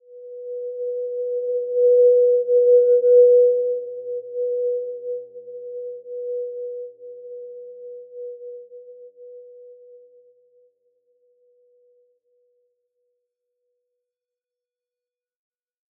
Simple-Glow-B4-mf.wav